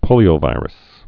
(pōlē-ō-vīrəs)